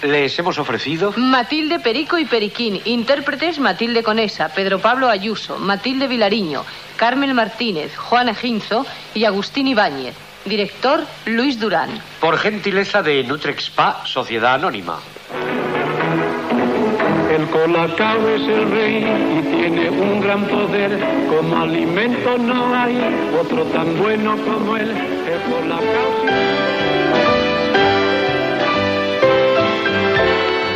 Careta de sortida amb els crèdits i la propaganda de Nutrexpa
Ficció